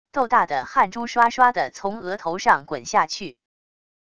斗大的汗珠刷刷的从额头上滚下去wav音频生成系统WAV Audio Player